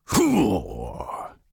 B_pain1.ogg